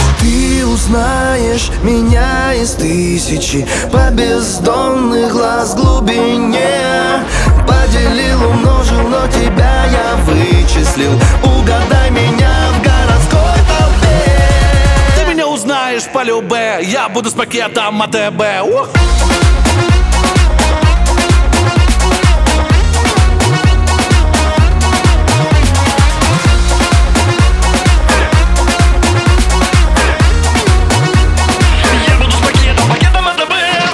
• Качество: 320, Stereo
забавные
веселые
заводные
энергичные